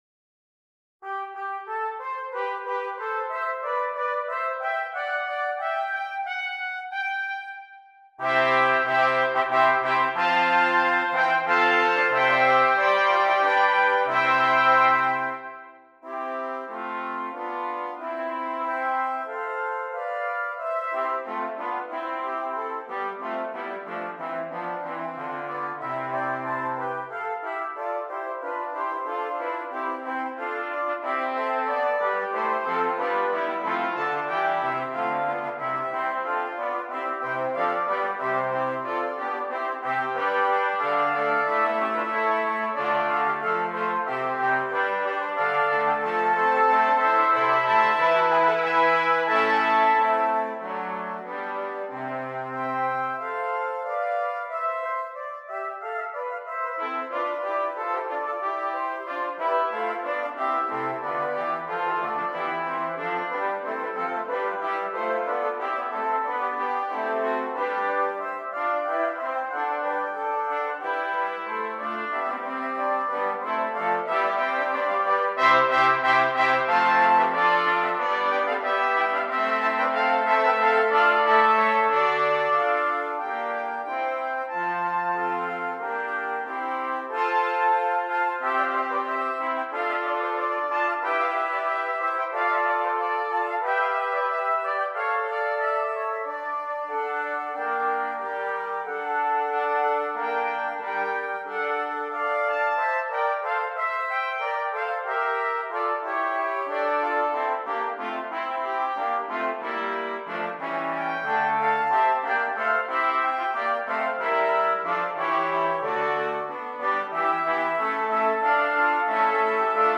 Brass
6 Trumpets and Bass Clef Instrument